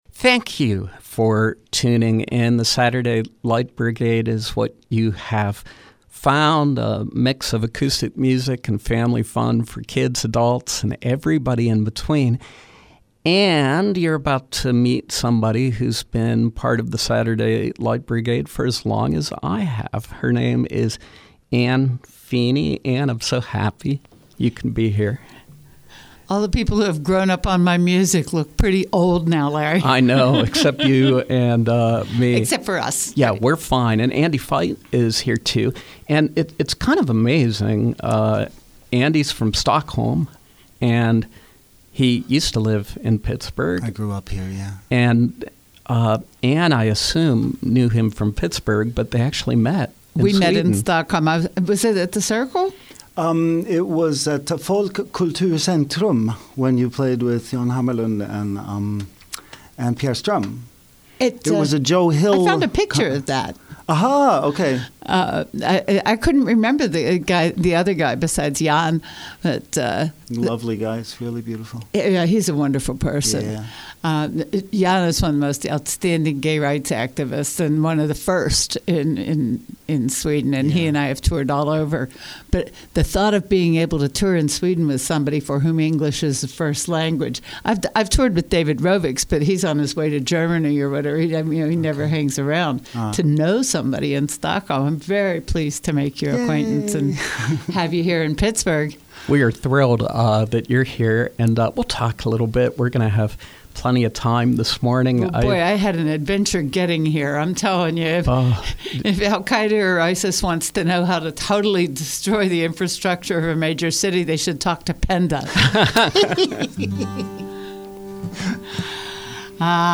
Stockholm-based jazz guitarist